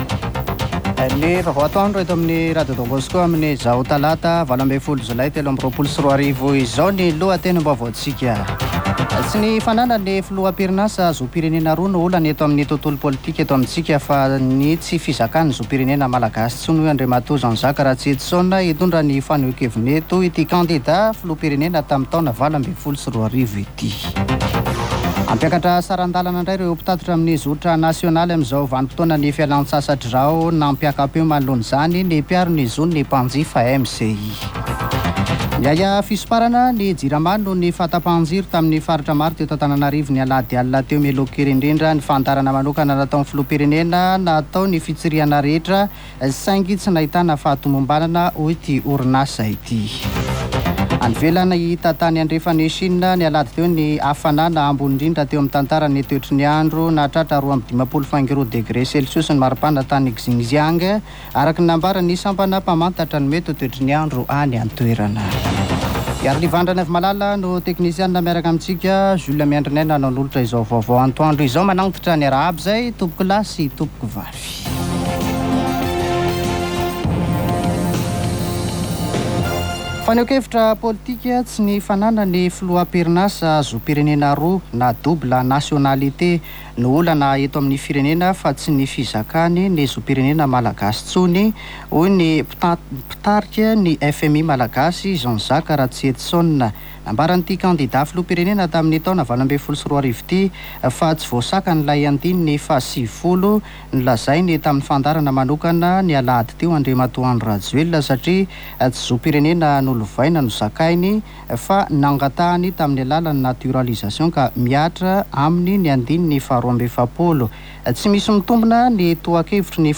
[Vaovao antoandro] Talata 18 jolay 2023